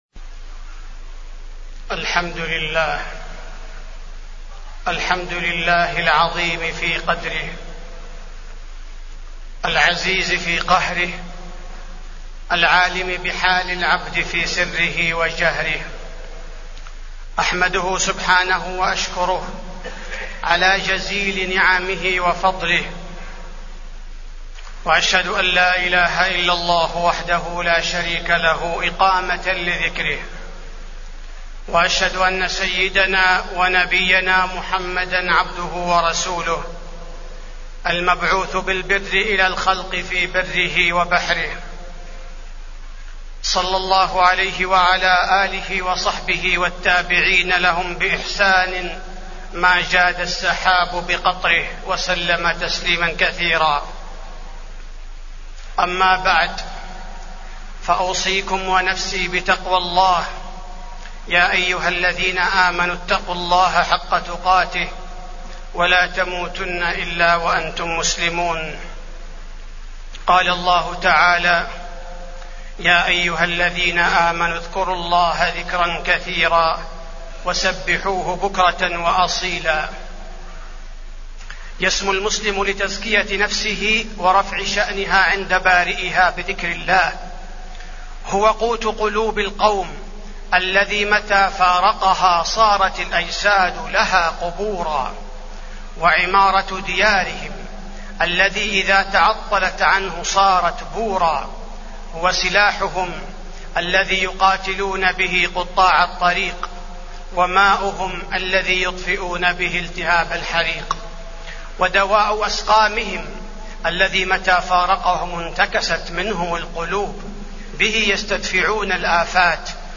تاريخ النشر ٩ جمادى الآخرة ١٤٢٩ هـ المكان: المسجد النبوي الشيخ: فضيلة الشيخ عبدالباري الثبيتي فضيلة الشيخ عبدالباري الثبيتي ذكر الله عز وجل